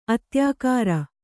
♪ atyākāra